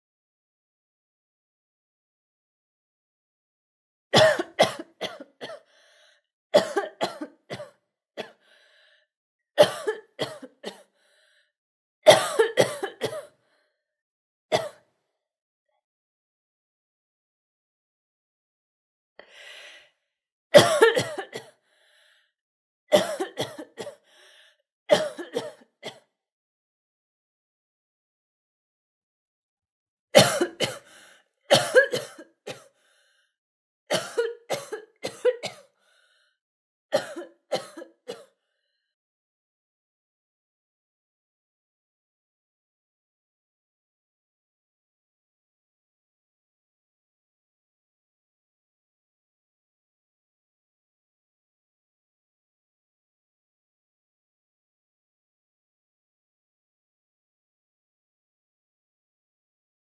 دانلود صدای سرفه – مرد،زن و بچه از ساعد نیوز با لینک مستقیم و کیفیت بالا
جلوه های صوتی